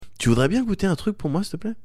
Tags: Cosy Corner Bruits de Bouches rires